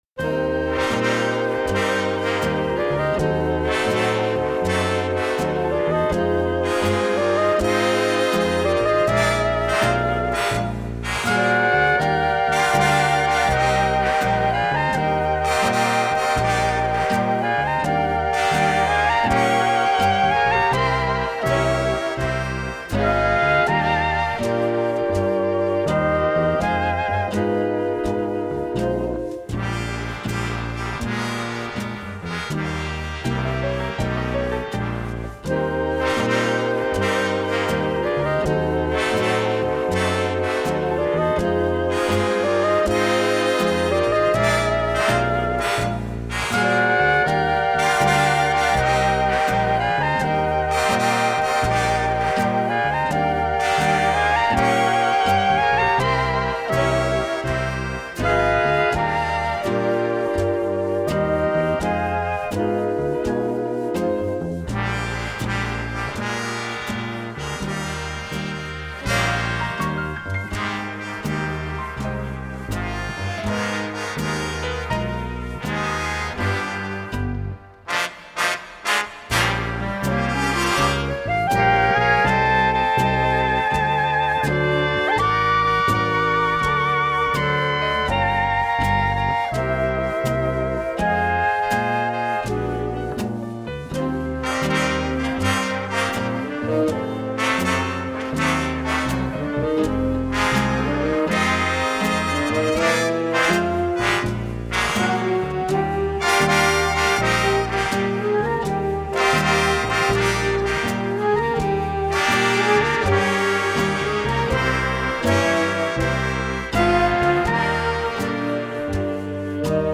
И немного для вечернего настроения джазовой музыки.